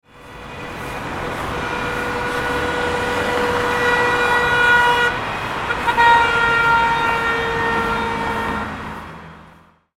Car-passing-and-honking-sound-effect.mp3